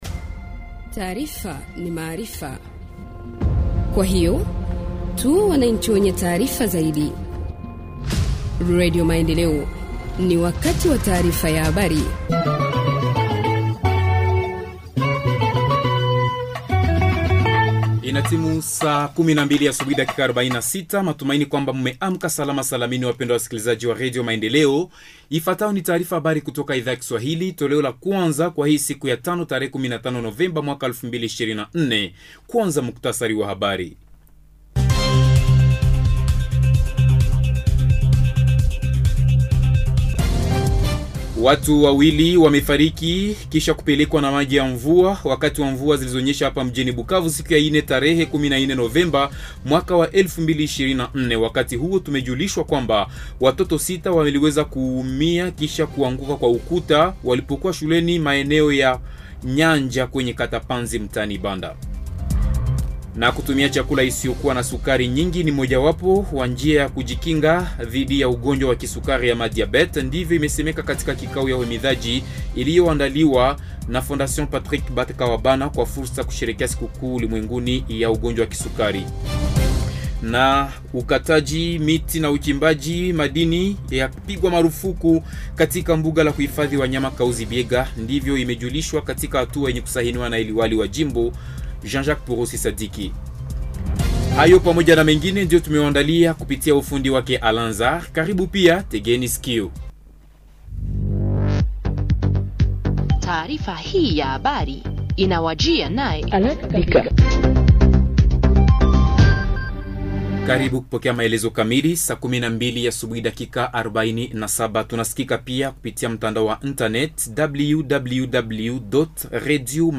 Journal en Swahili du 15 novembre 2024 – Radio Maendeleo